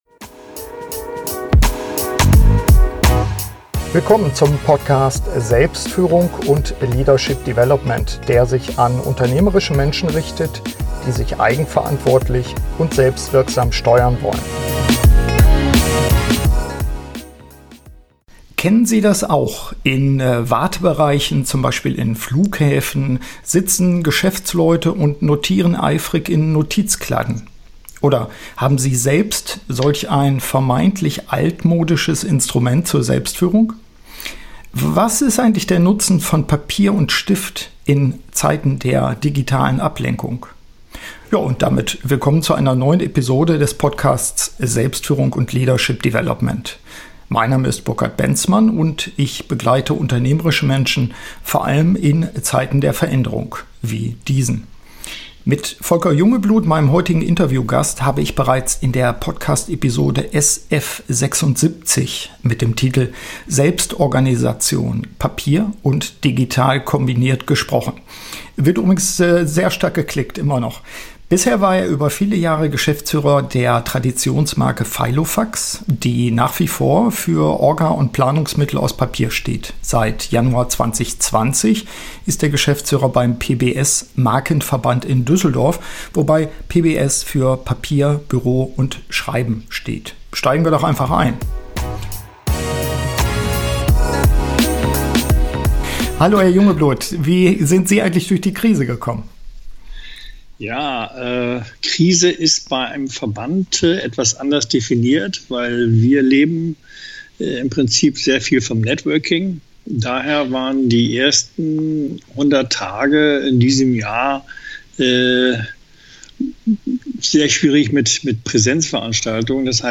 Update-Gespräch